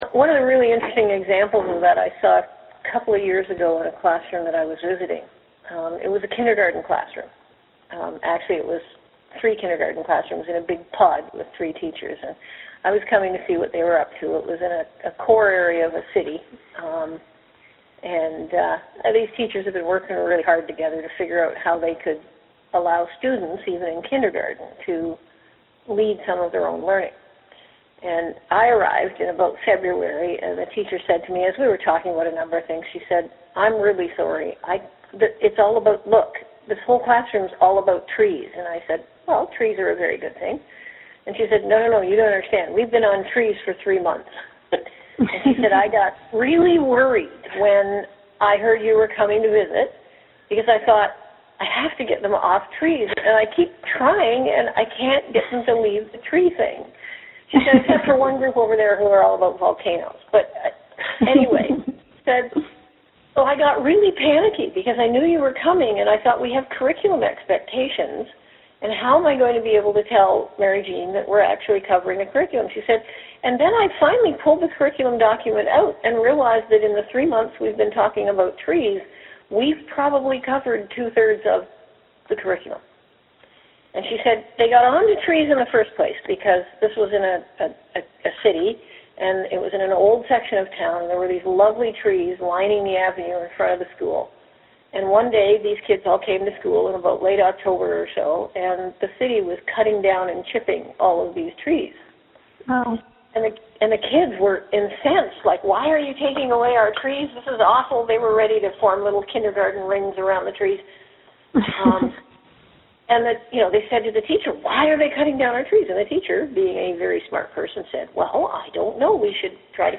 Posted in About K-12 International Education News, Interviews